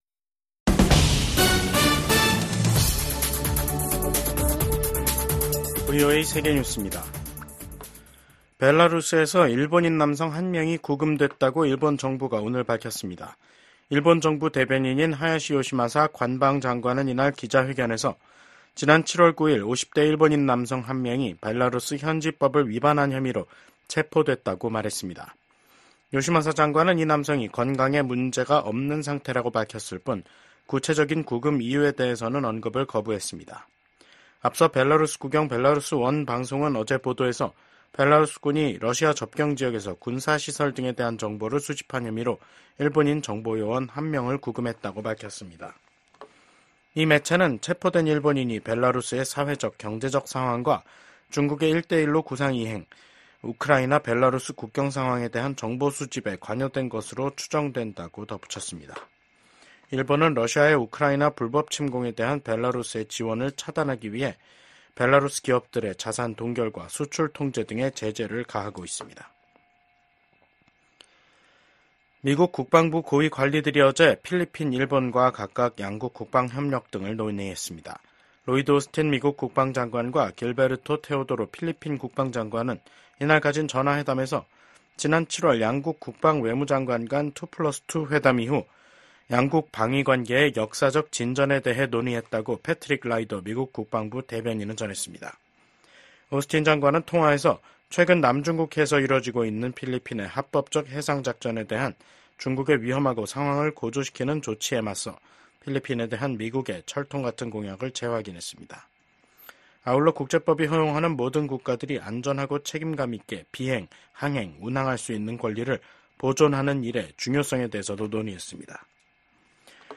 VOA 한국어 간판 뉴스 프로그램 '뉴스 투데이', 2024년 9월 5일 2부 방송입니다. 미국과 한국이 북한의 도발에 대한 강력한 대응 의지를 재확인했습니다. 북한이 25일만에 또 다시 한국을 향해 쓰레기 풍선을 날려 보냈습니다. 국제 핵실험 반대의 날을 맞아 북한의 핵과 미사일 개발을 규탄하는 목소리가 이어졌습니다.